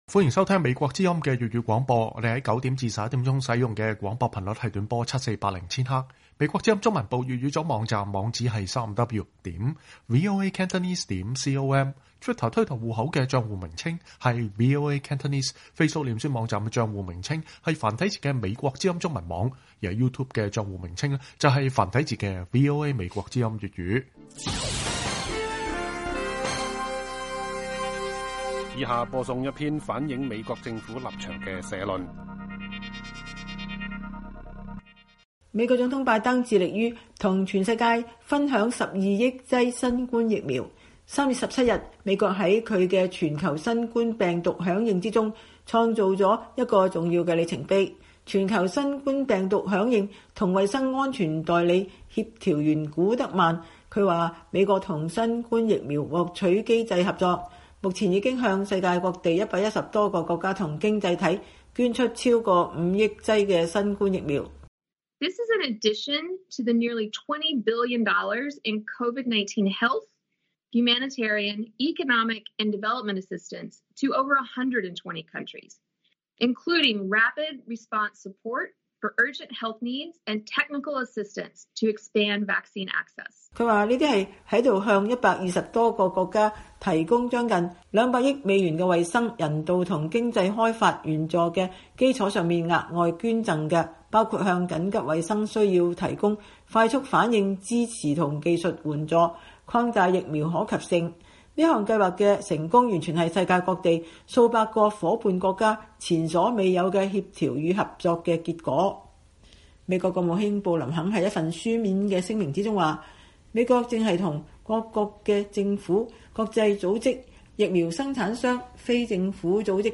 以下是一篇反映美國政府政策立場的社論：